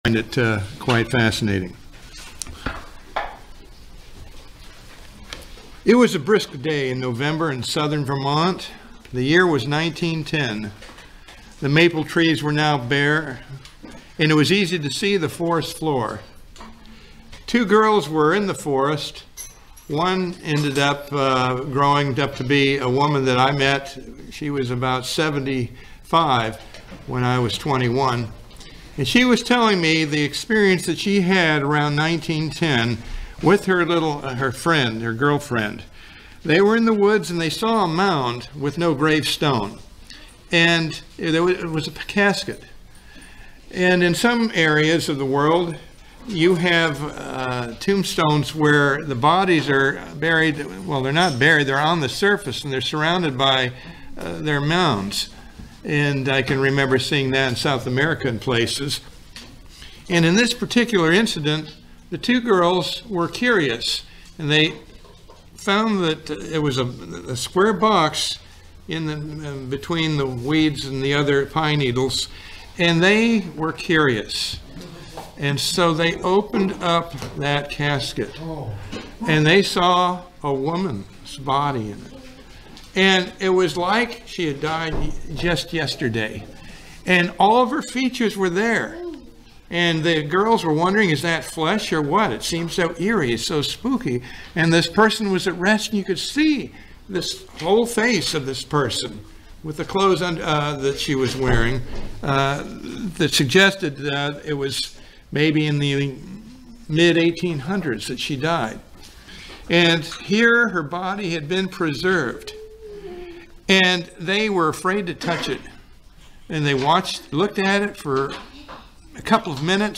This sermon is designed to explain how God will eventually reconcile the human race to Himself through 3 stages.